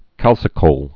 (kălsĭ-kōl)